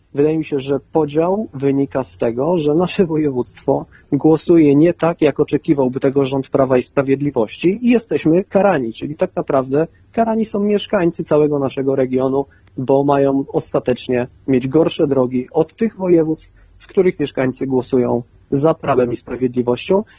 Tak niesprawiedliwy podział środków, jest spowodowany tym, że w regionie zachodniopomorskim nie rządzi  Prawo i Sprawiedliwość – mówi Marcin Biskupski Radny Miasta z Koalicji Obywatelskiej.